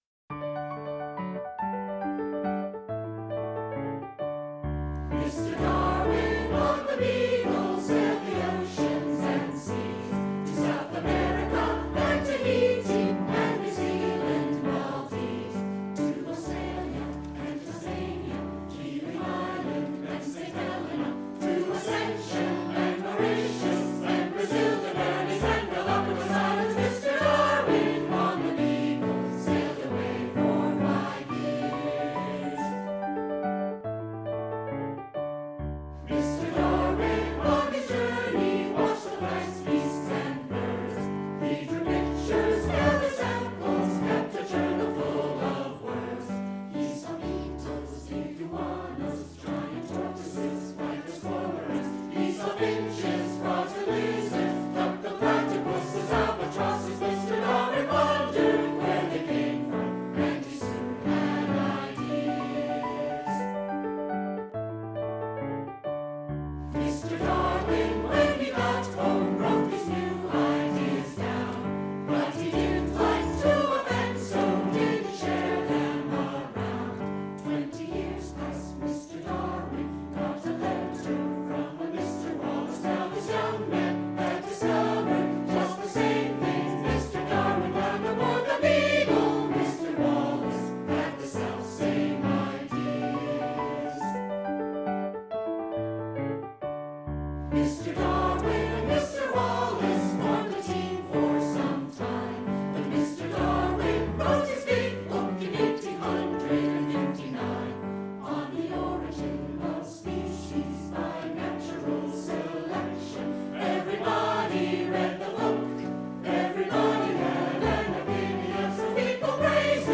• Mr. Darwin, Mr. Wallace, Mr. Matthew - The journeys of English naturalist and geologist Charles Darwin (1809 - 1882) aboard the Beagle are celebrated, appropriately enough, by this sea shanty.